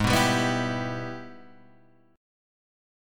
G# Minor Major 7th Sharp 5th